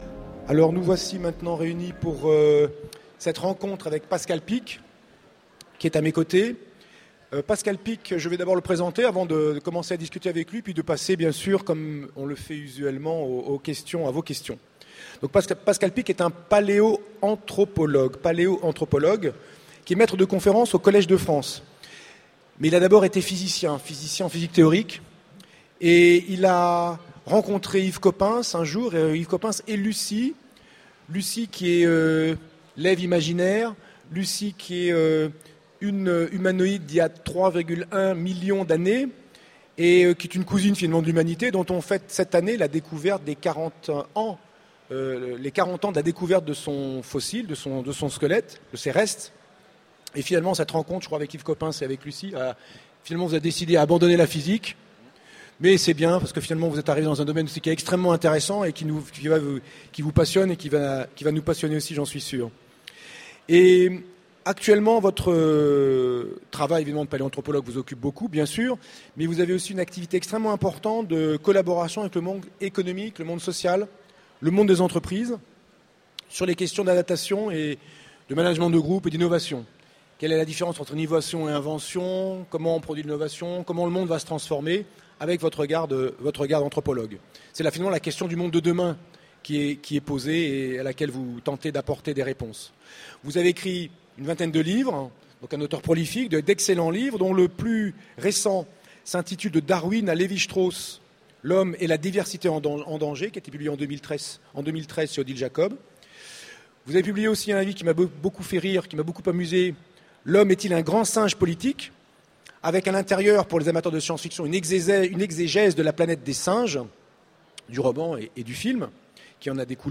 Utopiales 2014 : Rencontre avec Pascal Picq